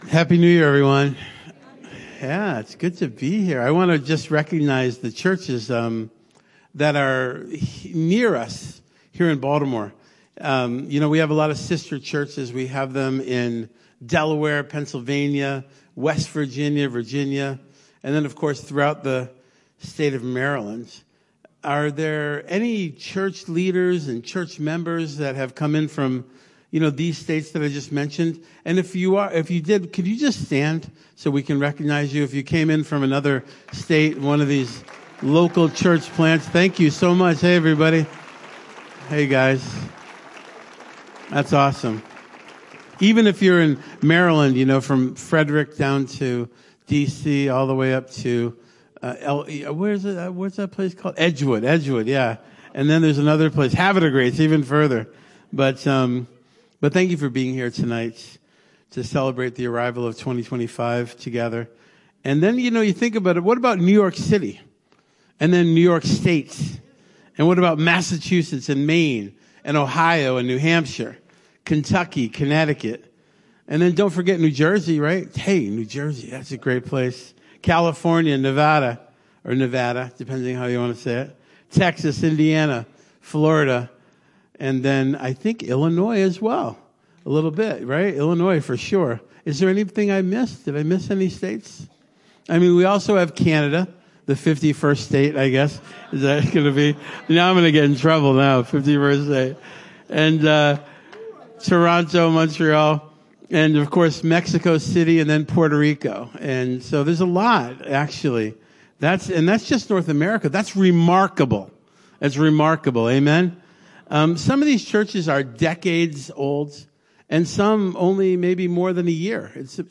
New Year’s Eve Service 2024. The Holy Pattern of Imitation.